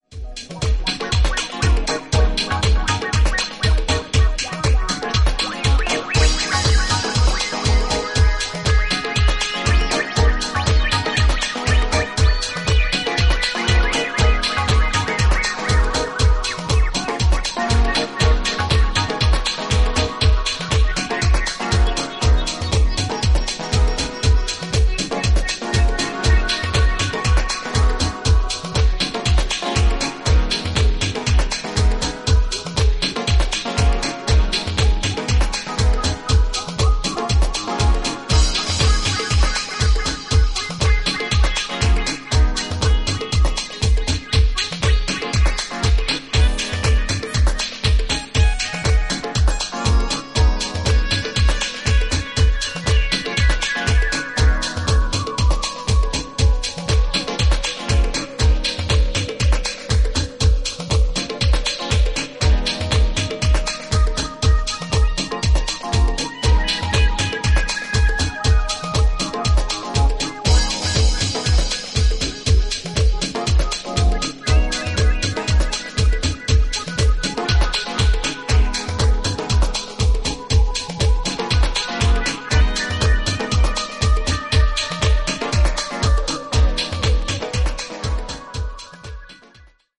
パーカッシッヴなリズムにメロディックなシンセか相まる